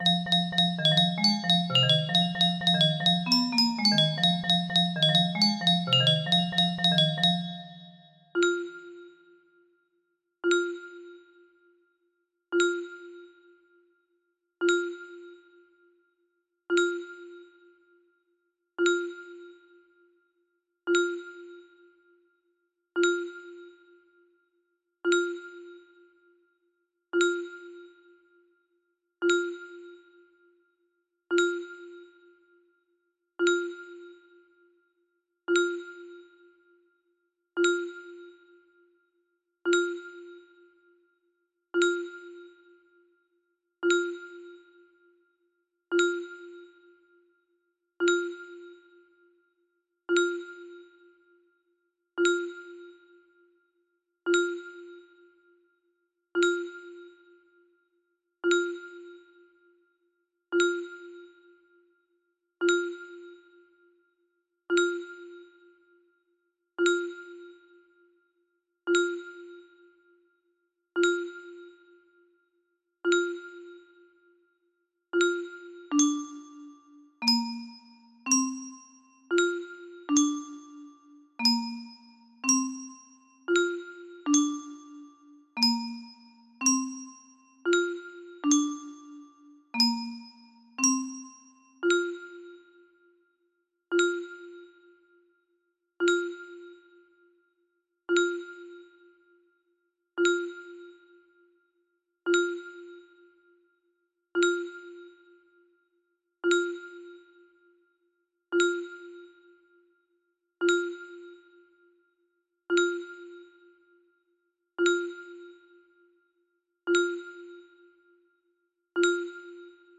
Full range 60
Imported from MIDI File